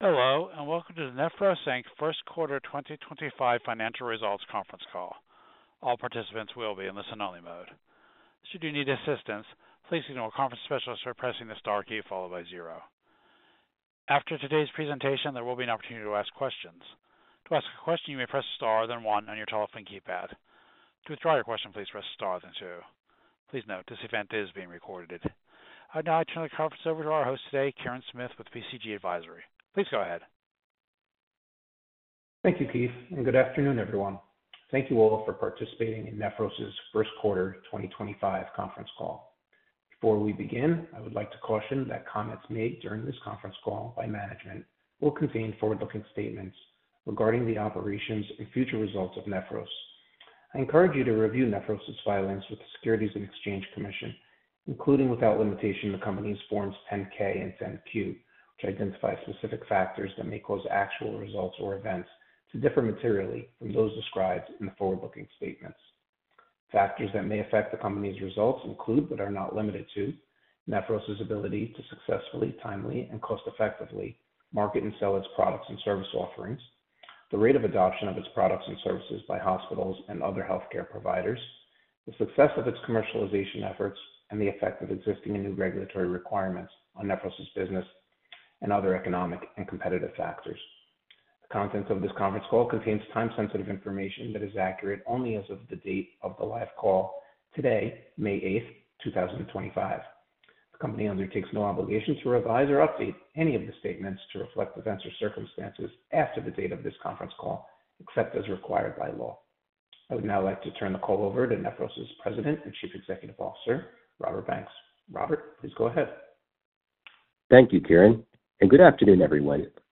Listen to the replay of the Q1 2025 conference call